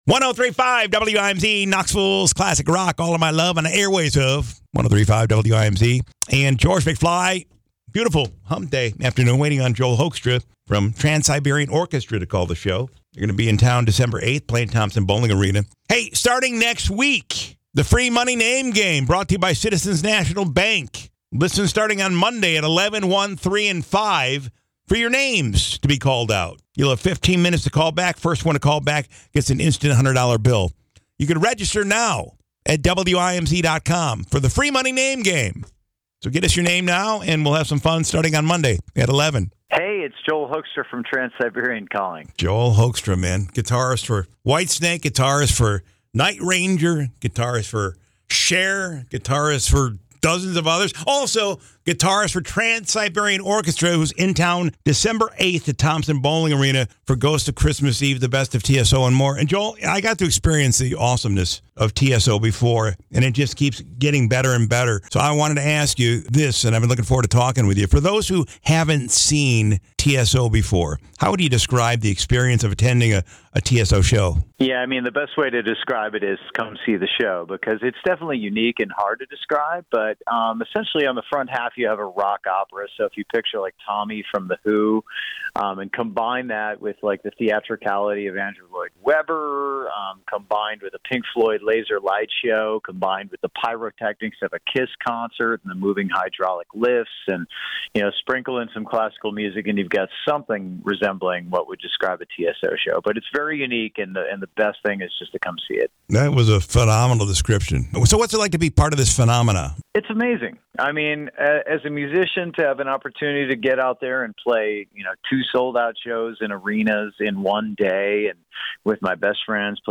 Transiberian Orchestra is coming to Knoxville Dec 8th, The Ghosts of Christmas Eve- the best of TSO and more at the Thompson Boling Arena, and guitarist, Joel Hoekstra called me on 1035 WIMZ!